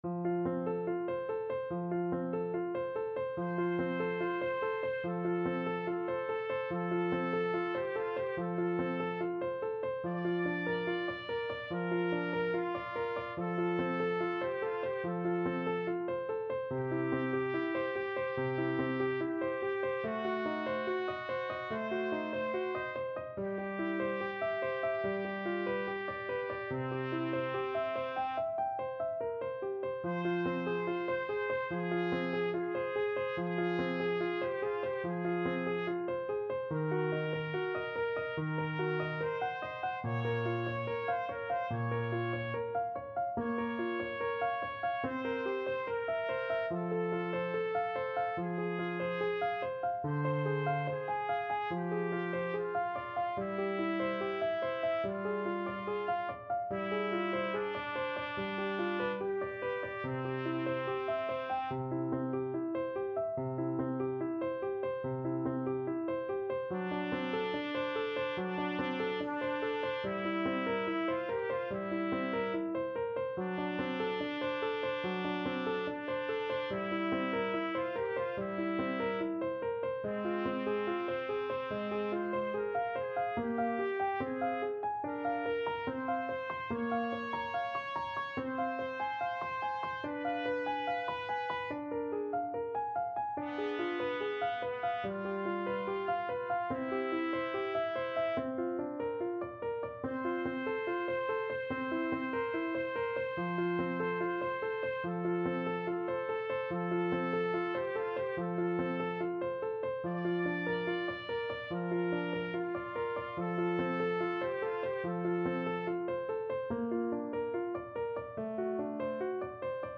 Andante =72
Classical (View more Classical Trumpet Duet Music)